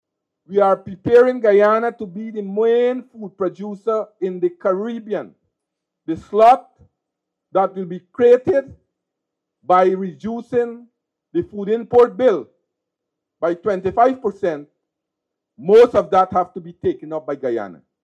Minister Mustapha, while addressing a crowd in Linden, Region 10, posited that the Government plans to transform agriculture in that Region, an which will result in Region 10 playing a crucial role in Guyana’s efforts to help the Caribbean achieve this 25 percent reduction.